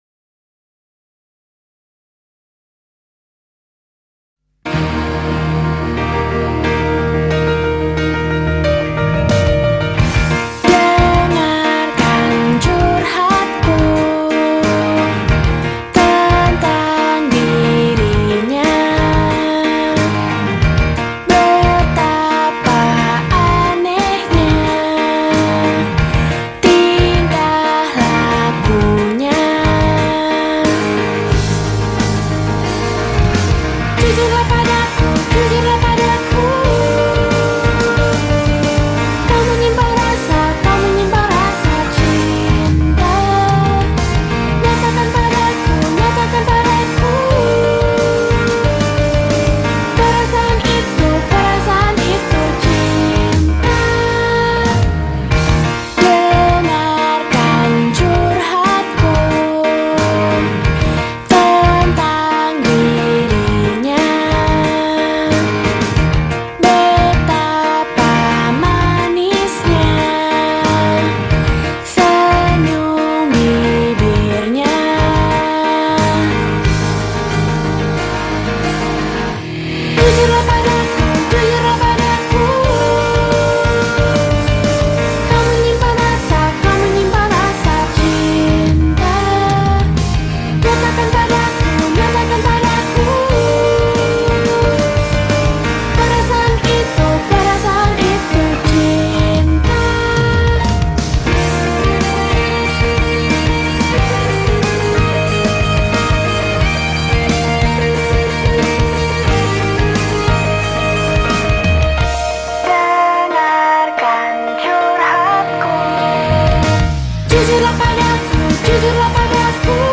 piano/keyboard
gitar
powerpop dan pop rock